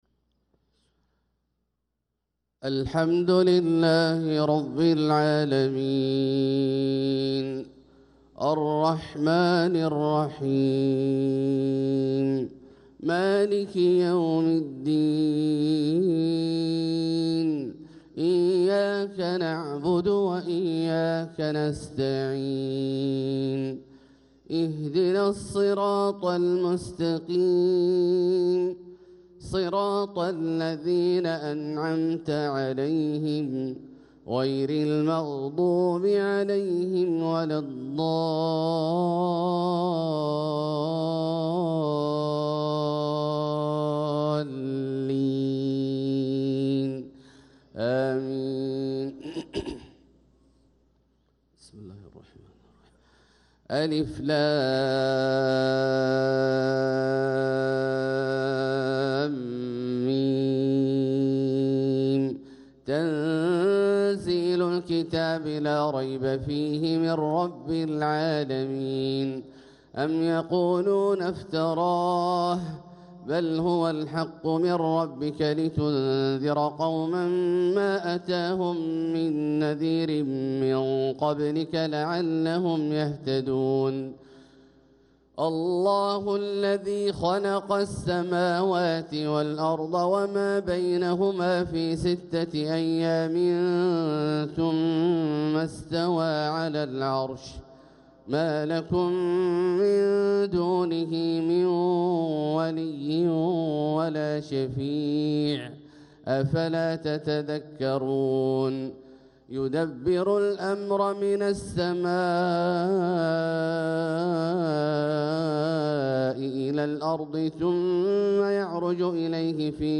صلاة الفجر للقارئ عبدالله الجهني 27 جمادي الأول 1446 هـ
تِلَاوَات الْحَرَمَيْن .